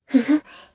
Kara-Vox_Happy1_jp.wav